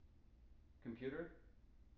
wake-word
tng-computer-99.wav